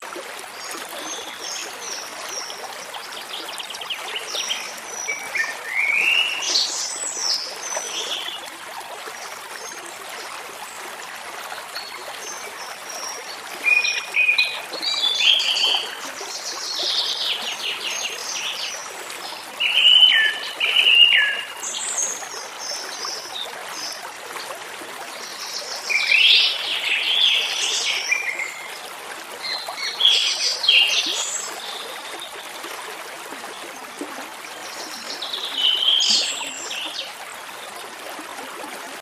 リラックスさせてくれる小川のせせらぎや自然の中を飛び交い美しい声の小鳥のさえずり、自然界の心地のいいリズムが癒しの空間を作り上げます。
小川のせせらぎと小鳥のさえずり 着信音